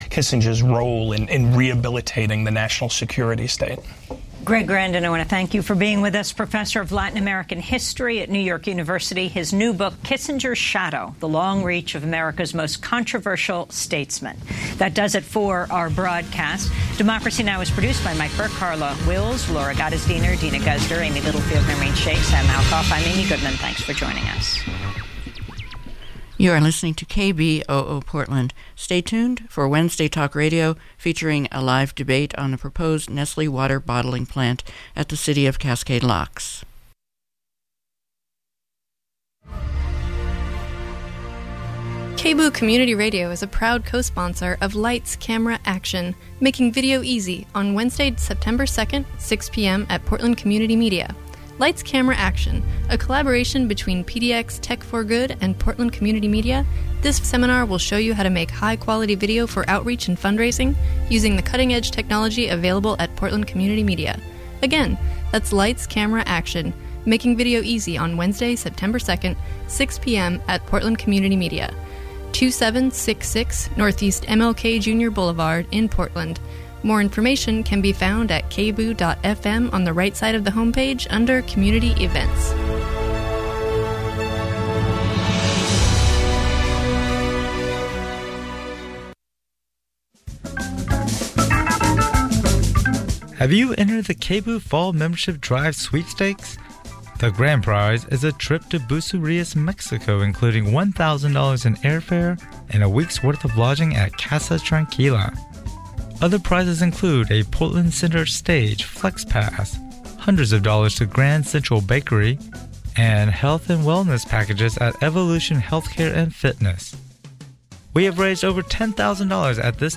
Download audio file In this live, on-air debate between the City Administrator of Cascade Locks, which has pushed for the Nestle faciltiy, and two members of the Local Water Alliance, which is opposed to it, we will explore the environmental and economic issues involved.